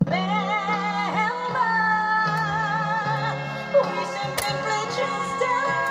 They all come from various audio cassettes.